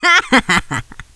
shelly_kill_05.wav